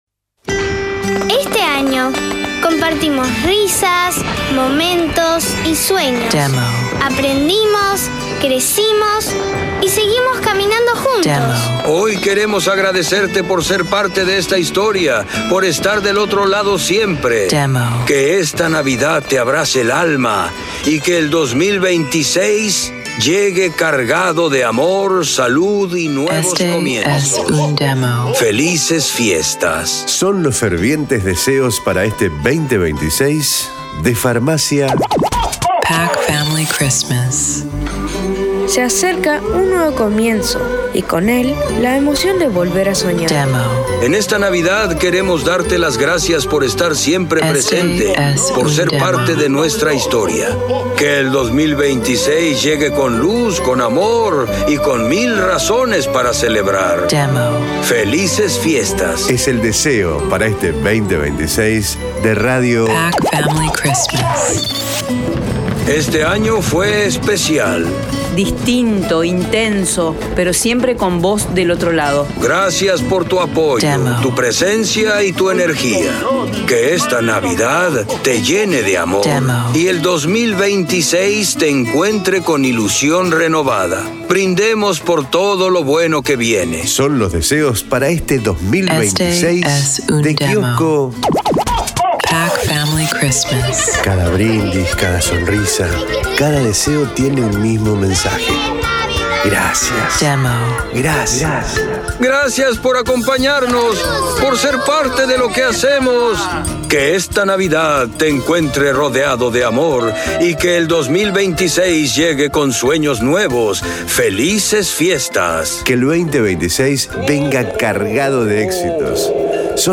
Esta producción  le dará  el típico sonido  navideños para compartir en familia.
Mensajes emotivos  para transmitir deseos de una forma cálida, cercana y con espíritu navideño..
El toque distintivo del pack son las intervenciones de Papá Noel, que aparece con frases breves y simpáticas para darle magia, unión y símbolo a estas fiestas.
DEMO_ARTE_NAVIDAD_TRADICIONAL.mp3